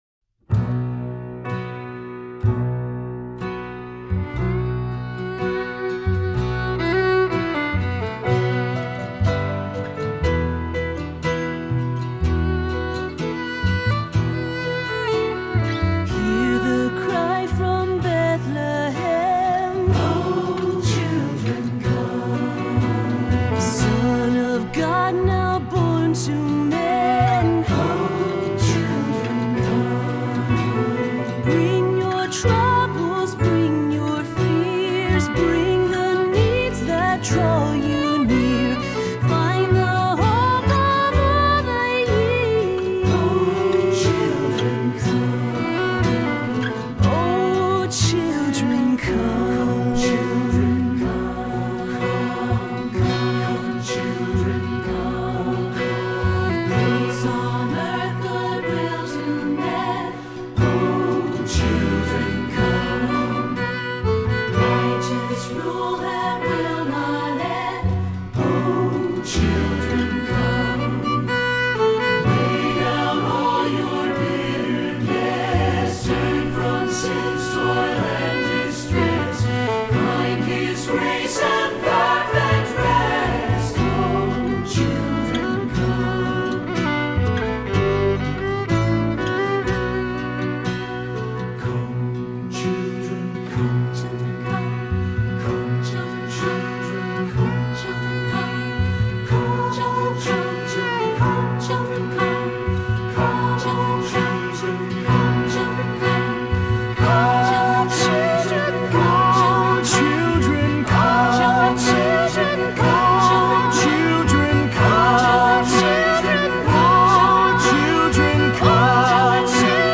Voicing: SATB/FIDDLE